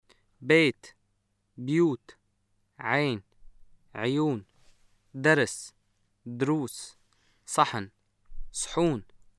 シリアのアラビア語 文法 名詞の複数形：例文
[beet (byuut), ʕeen (ʕyuun), dars (druus), Saħan (Sħuun)]